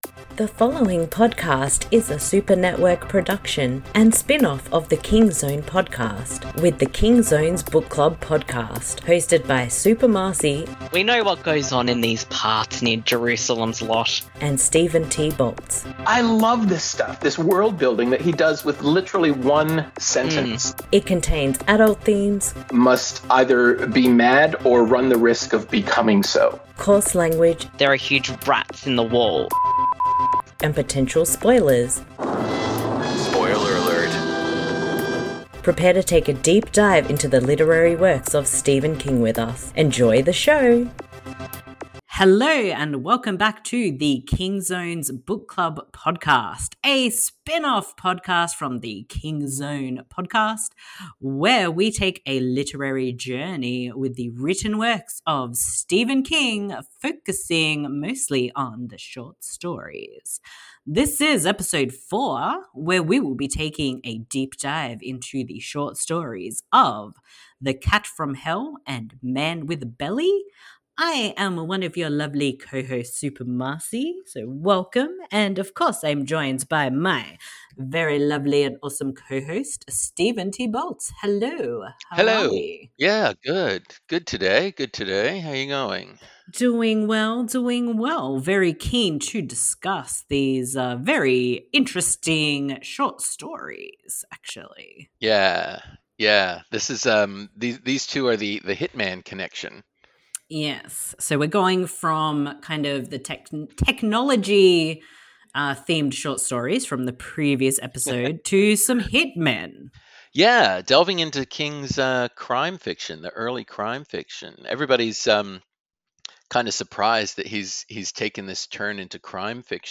The King Zone’s Book Club Podcast Episode 04 The Hitman Short Stories Discussion with The Cat From Hell and Man With A Belly